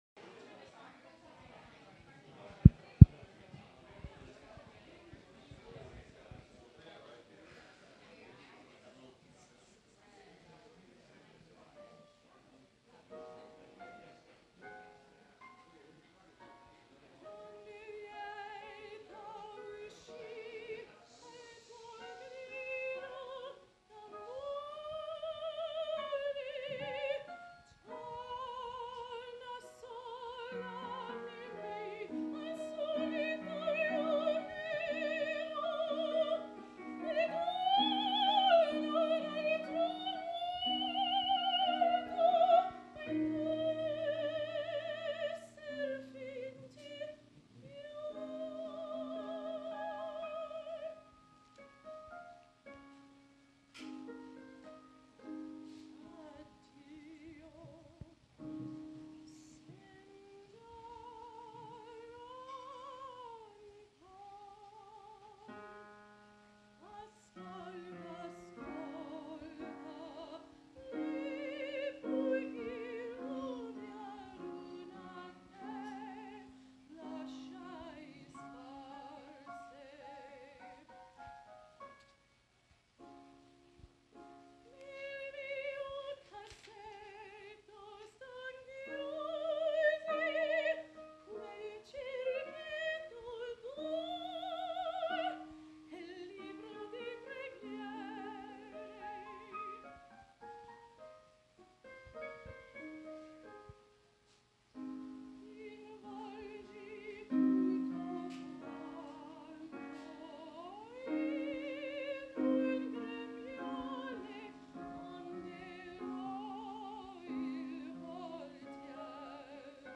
Opera & jazz singer
La Soiree Rouge People Lounge, Manhattan, NY February 2
Piano
Saxophone
Flugelhorn
Bass